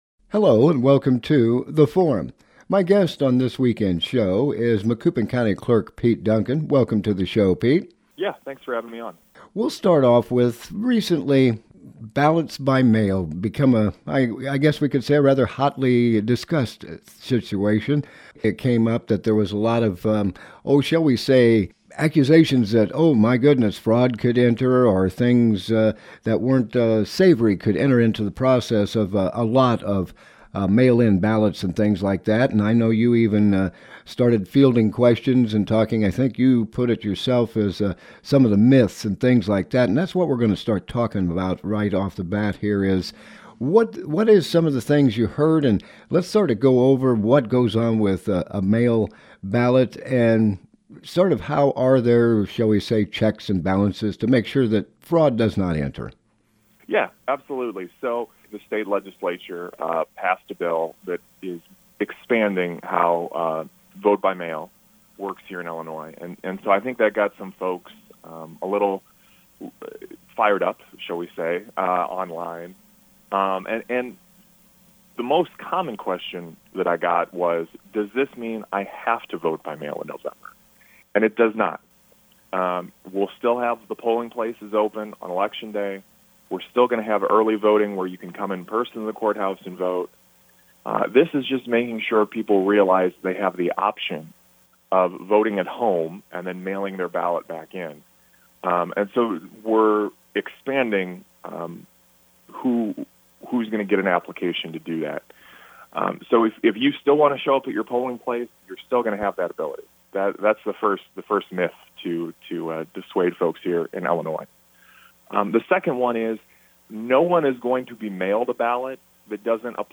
Topic: Vote by Mail Guest: Pete Duncan - Macoupin County Clerk